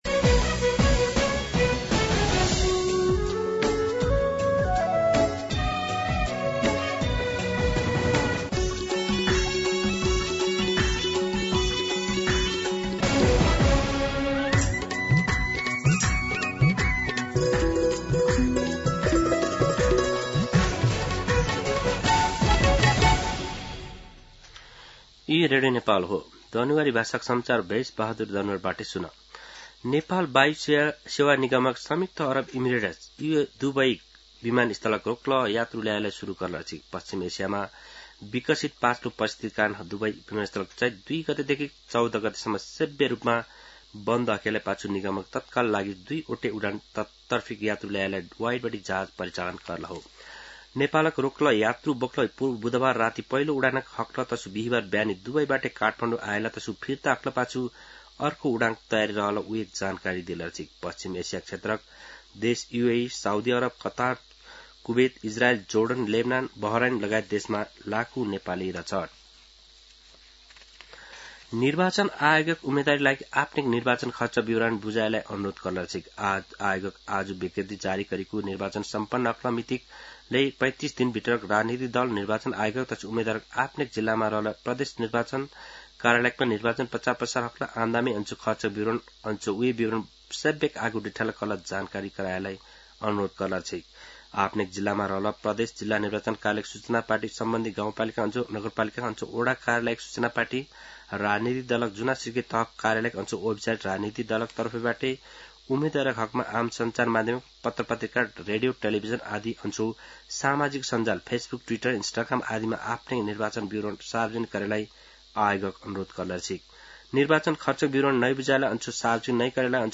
दनुवार भाषामा समाचार : २९ फागुन , २०८२